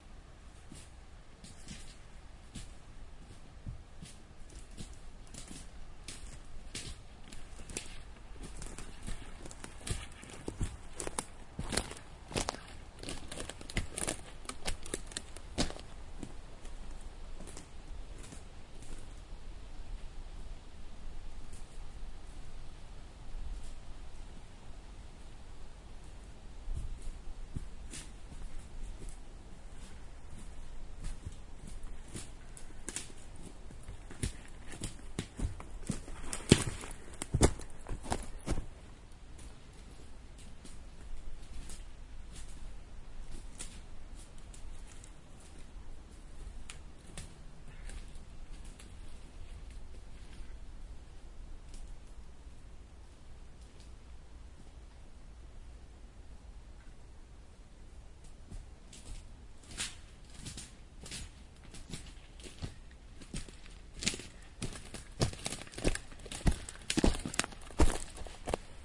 描述：这是上海郊区交通路口的现场记录。可以听到交通声音和路人的声音和脚步声以及附近商店的电子通告。
Tag: 摩托车 交通 环境 亚洲 中国 街道 郊区 上海 汽车 行人 中国 现场记录 南汇